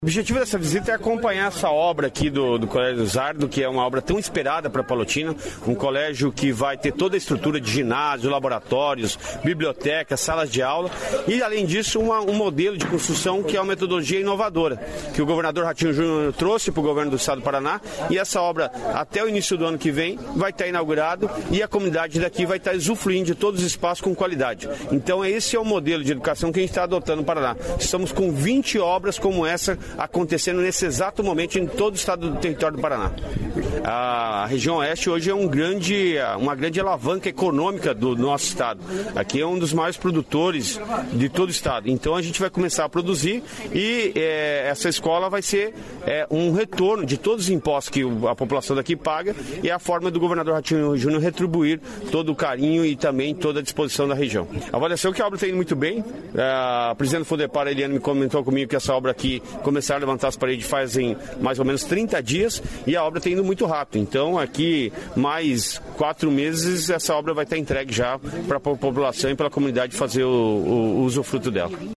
Sonora do secretário de Estado da Educação, Roni Miranda, sobre novo colégio estadual de Toledo